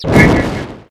singFlyby.ogg